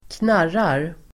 Uttal: [²kn'ar:ar]